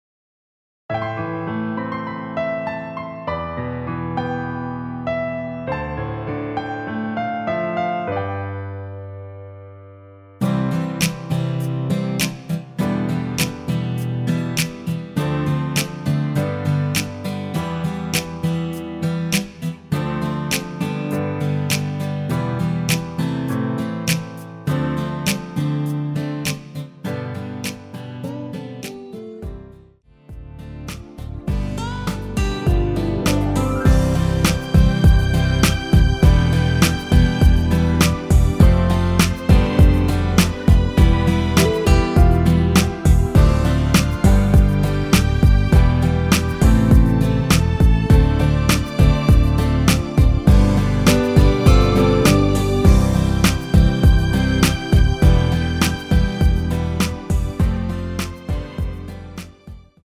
(-3)내린 MR 입니다.
앞부분30초, 뒷부분30초씩 편집해서 올려 드리고 있습니다.
중간에 음이 끈어지고 다시 나오는 이유는